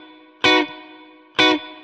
DD_StratChop_130-Bmaj.wav